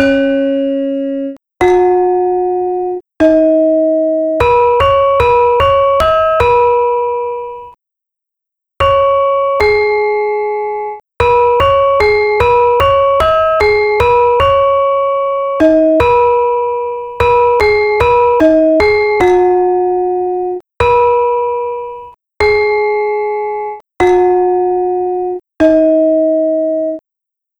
Conclusion of the balungan for Ladrang Siyem sequenced with samples from Kyahi Paridjata of the gamelan group Marsudi Raras of Delft